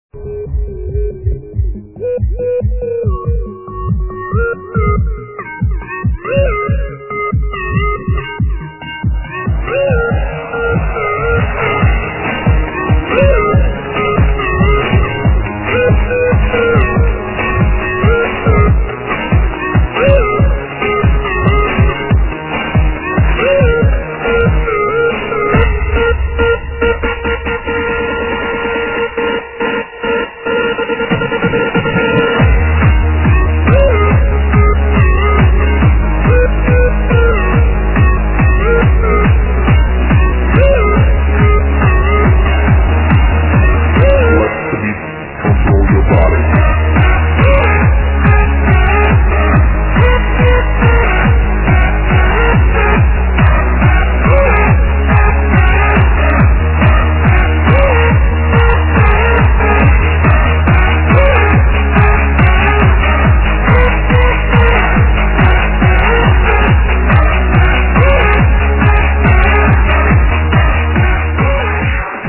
Can somebody help me ID this CRAZZZYYY trance song?